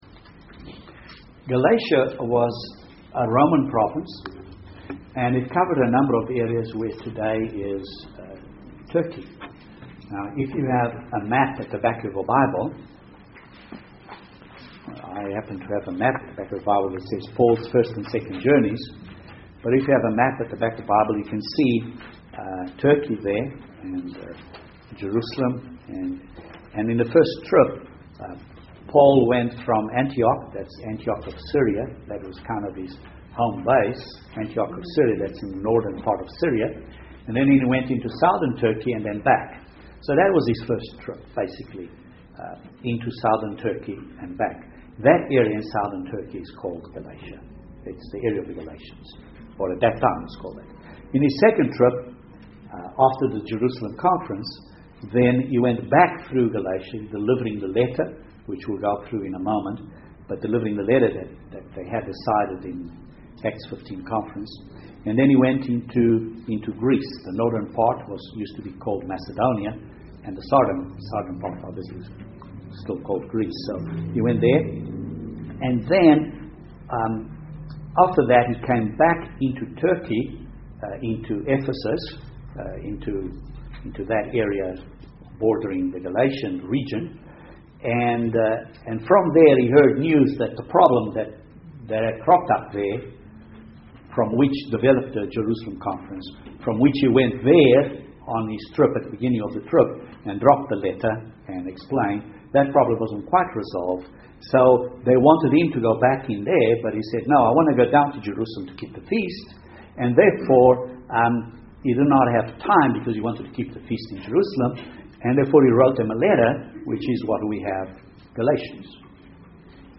Introduction to Galatians until Gal 1:5 UCG Sermon Transcript This transcript was generated by AI and may contain errors.